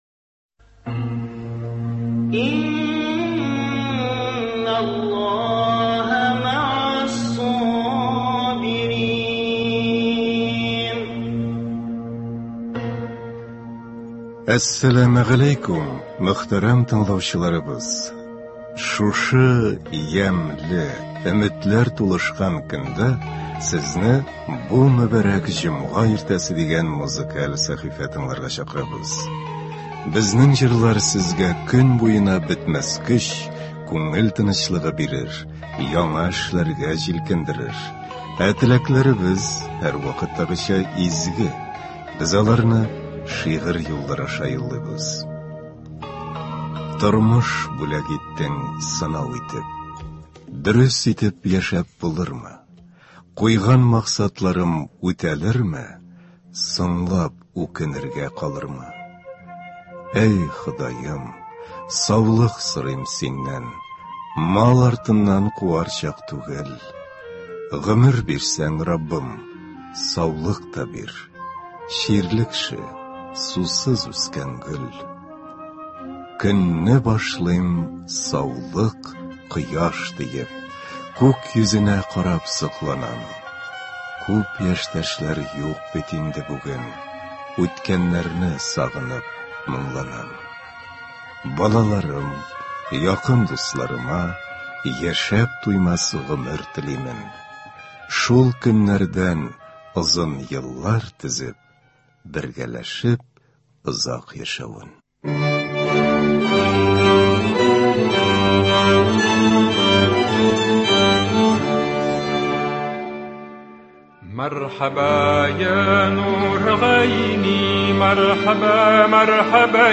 Бу иртәне дә күңелле җырлар белән башлап җибәрәбез!